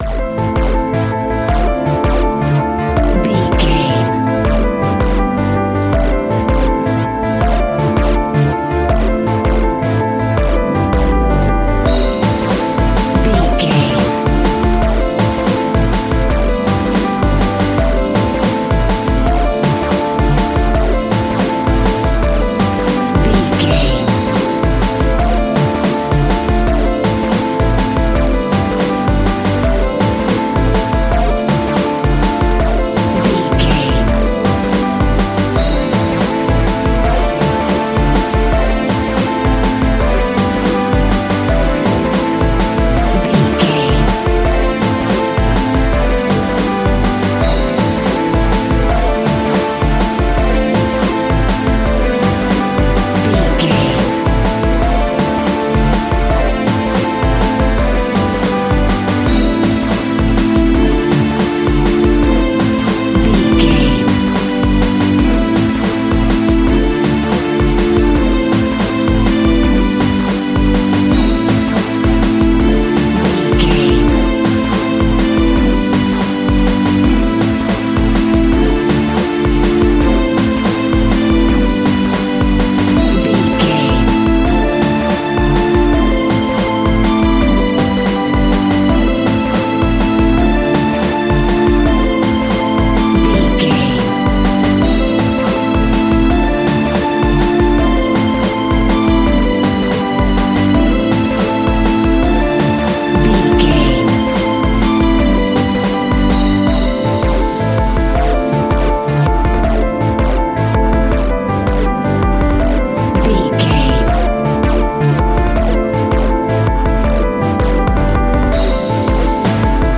Aeolian/Minor
Fast
driving
energetic
futuristic
hypnotic
frantic
drums
synthesiser
piano
break beat music
synth bass
synth lead
synth pad
robotic